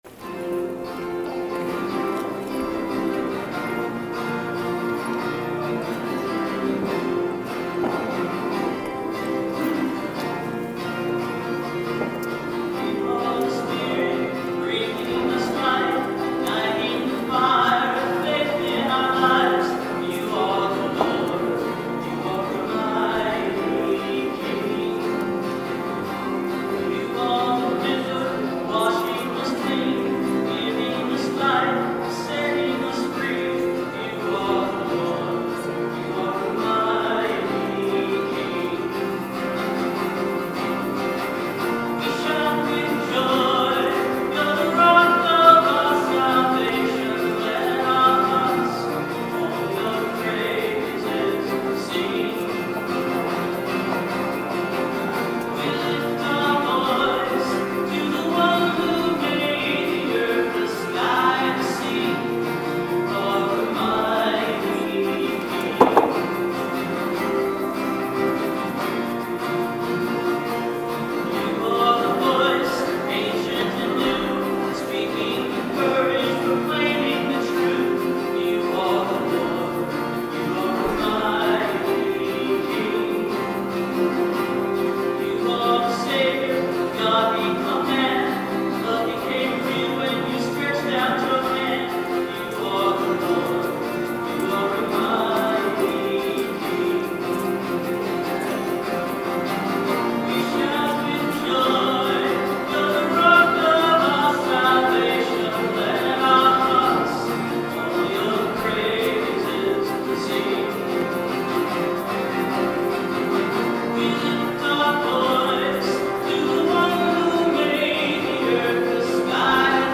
11/24/13 Mass Recording of Music